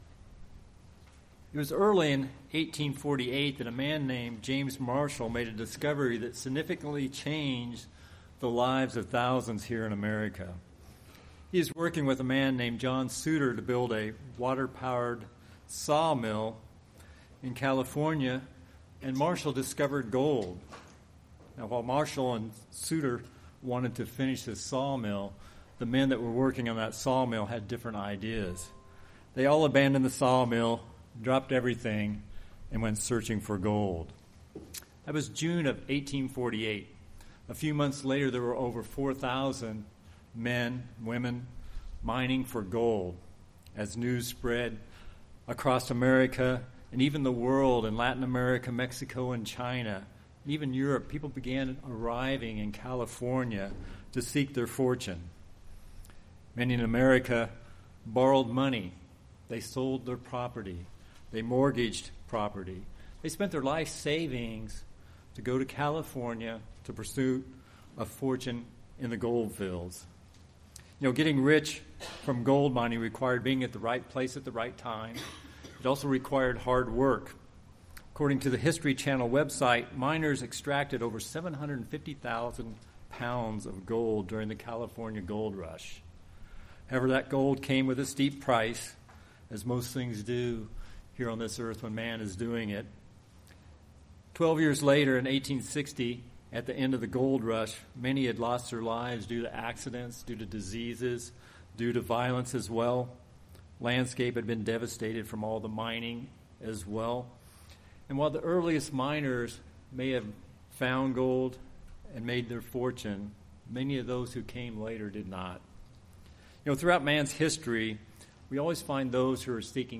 In this sermon we’ll learn how we can become the spiritual temple God wants each of us to be.